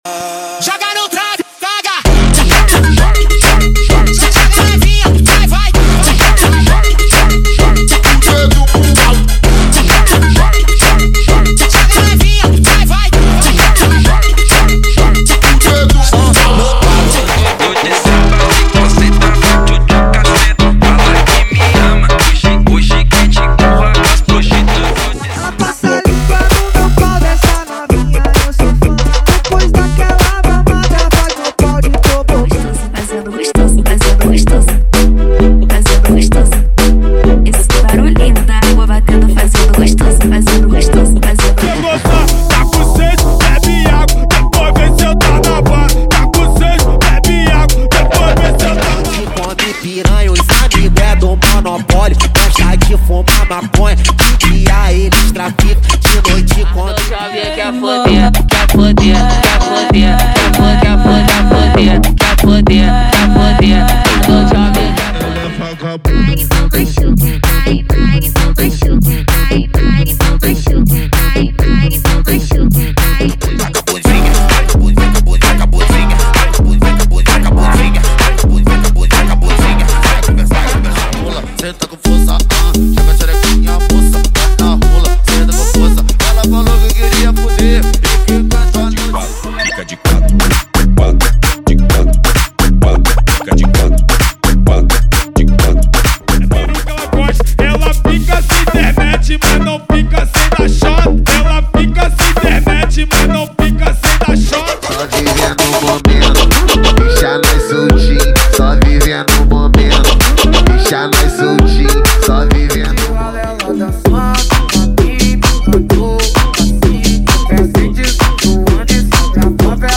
ELETRO FUNK LIGHT: